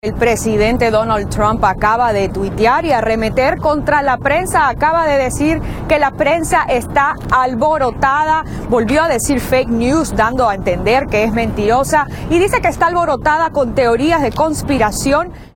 Esta mañana tuiteó contra los periodistas que buscan, supuestamente, hallar teorías de conspiración. Por su parte los demócratas buscan seguir las investigaciones sobre las pláticas que tenían los asesores de Trump con los rusos. (La periodista